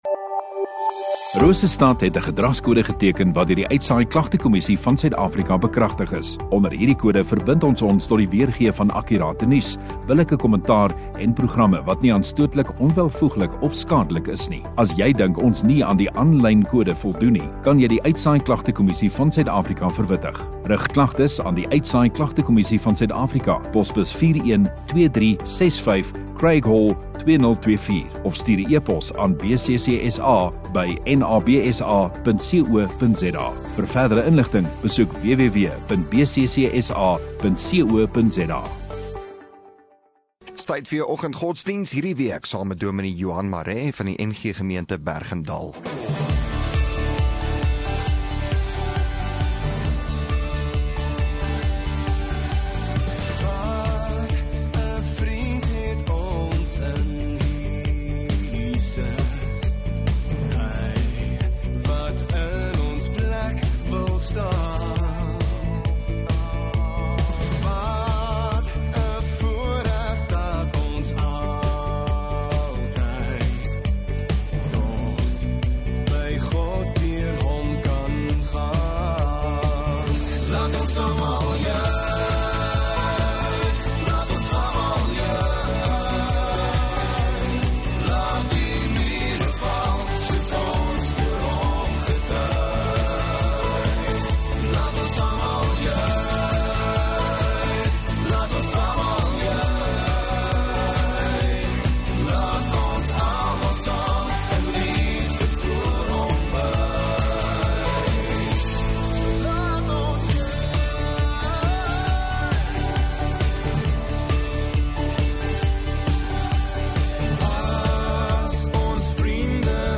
1 Dec Maandag Oggenddiens